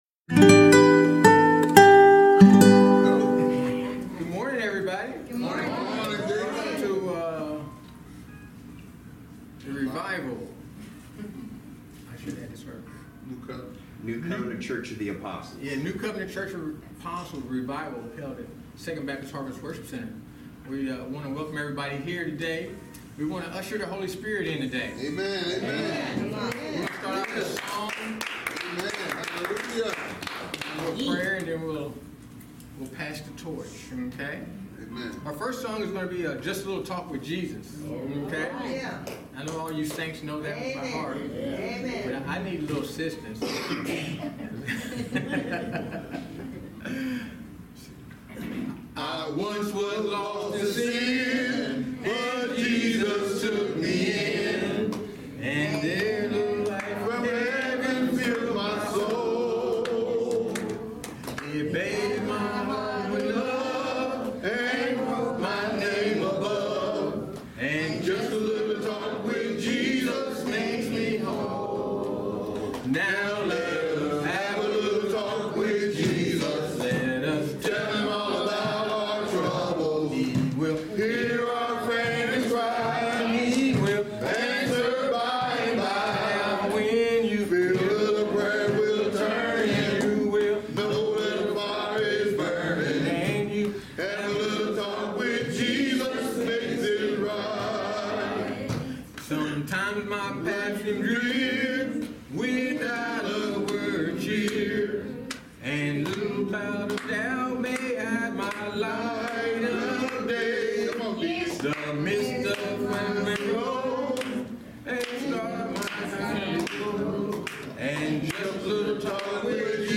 In this final day of the Great Return 2024, We have Warriors for Christ leading us in anointed worship.
Service Type: Revival Service